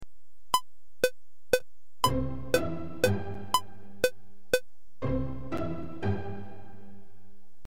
Note, these are all the same tempo; 120 BPM (beats per minute).